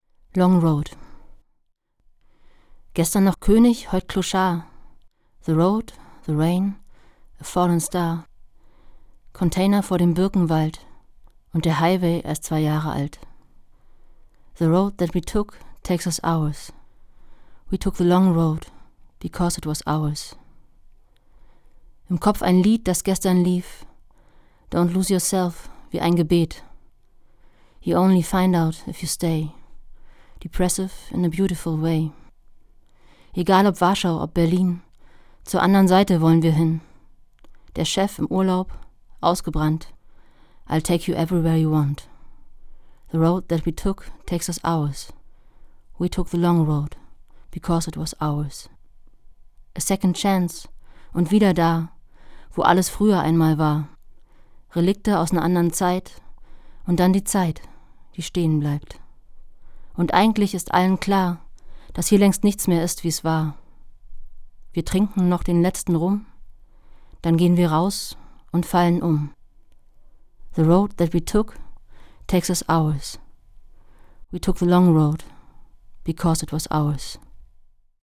Das radio3-Gedicht der Woche: Dichter von heute lesen radiophone Lyrik.